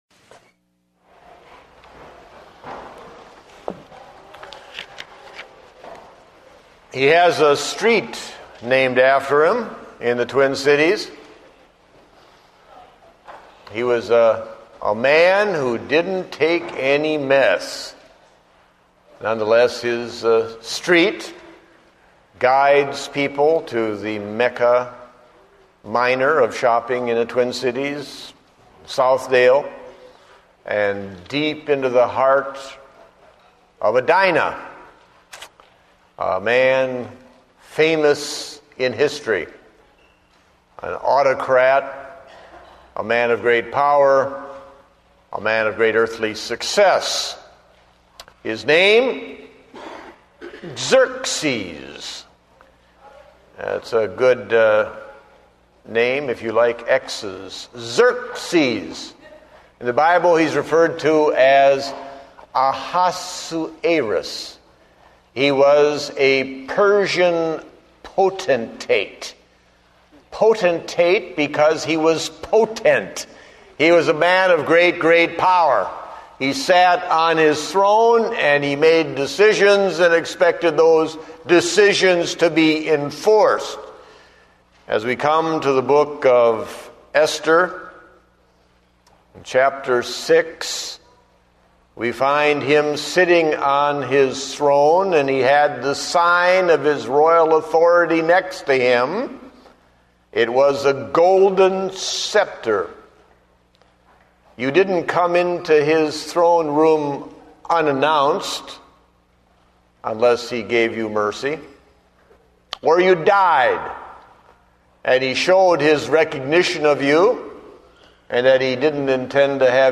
Date: June 28, 2009 (Morning Service)